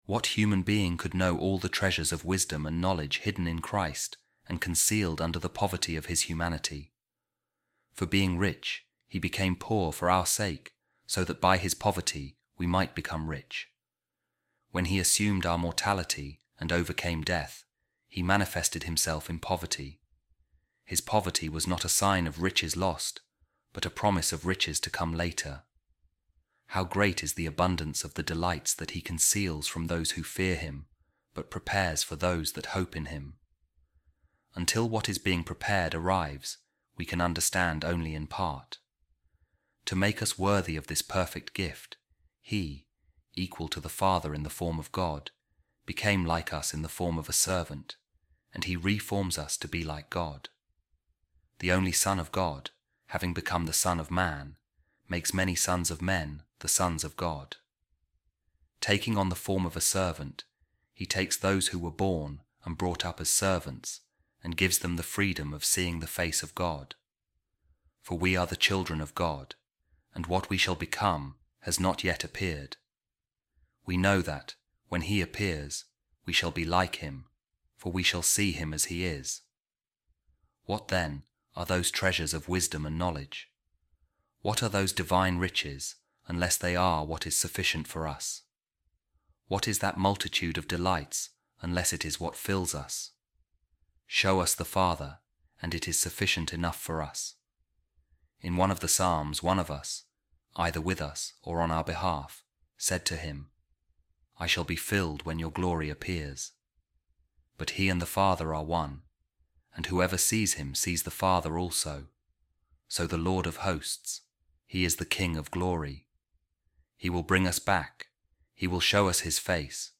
Office Of Readings | Christmastide | 5th January | A Reading From A Sermon By Saint Augustine | Our Desires Will Be Completed, Fulfilled, In The Vision Of The Word